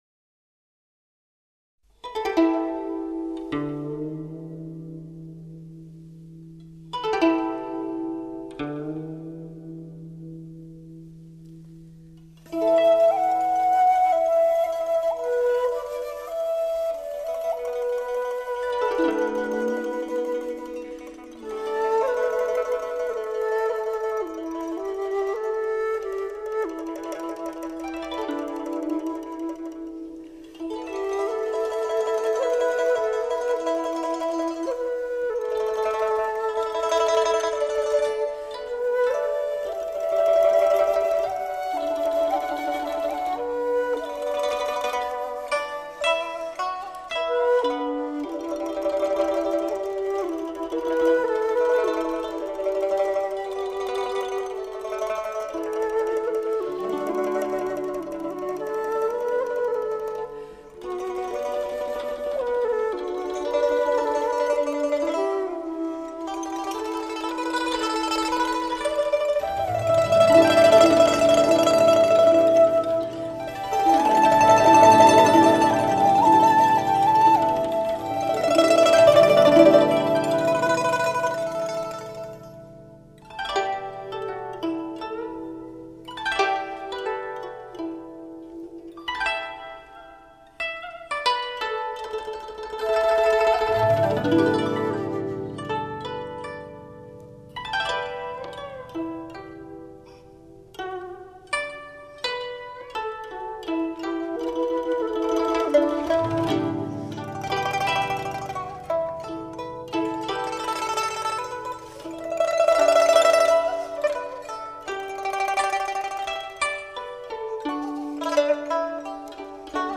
琵琶 筝 箫(笛 埙)重奏名曲集
追求音乐之灵魂 如歌如泣 情韵绵绵无绝期